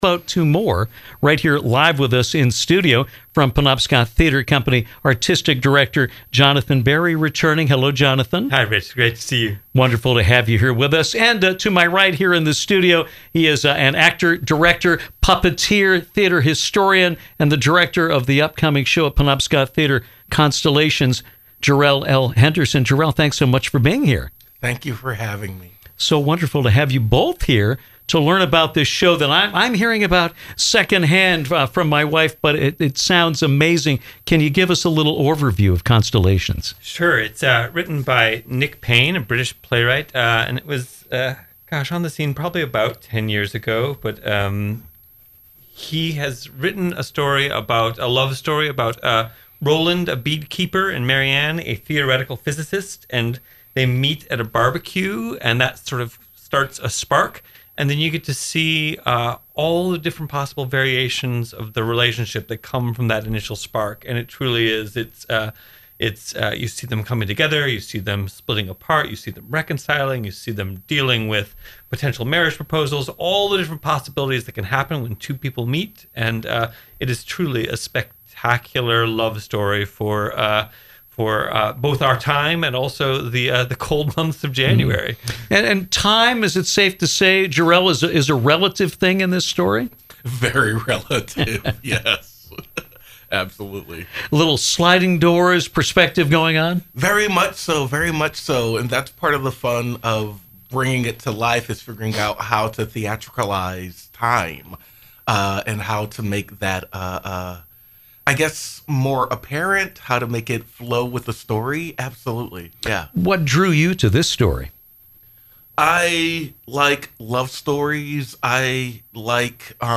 joined us in-studio to talk about their production of CONSTELLATIONS.